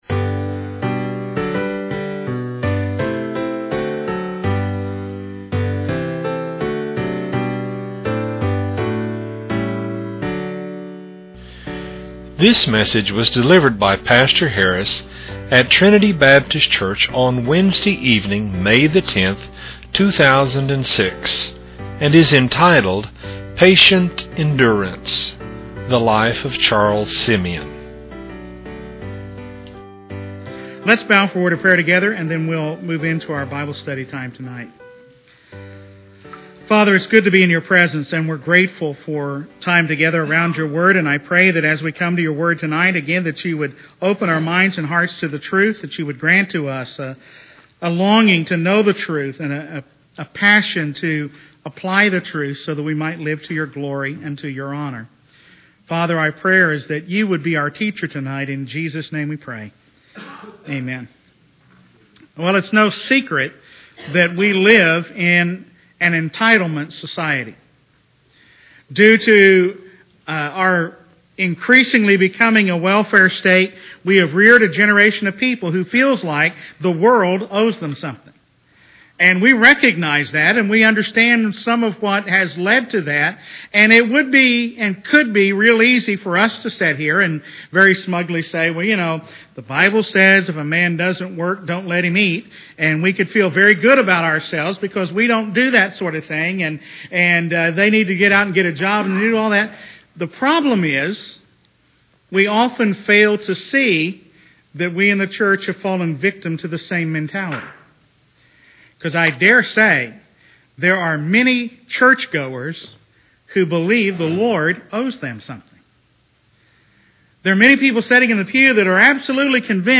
Biographical Sermons - TBCTulsa